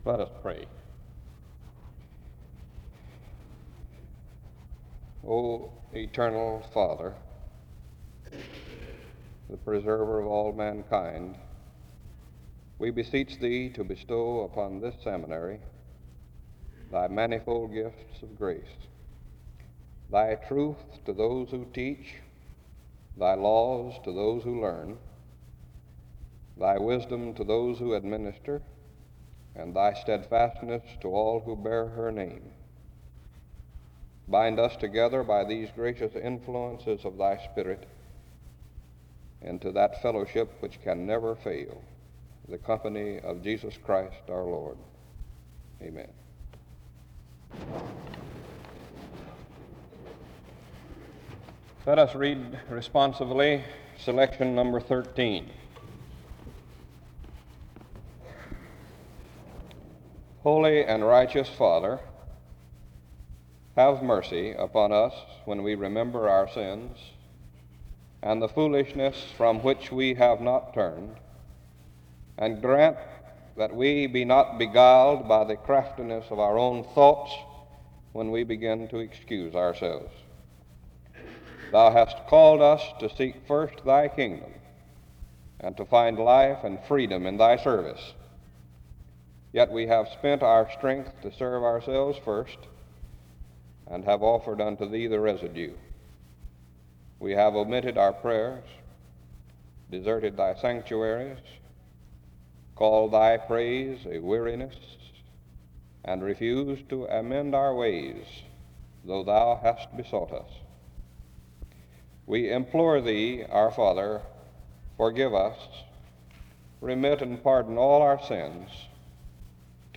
In Collection: SEBTS Chapel and Special Event Recordings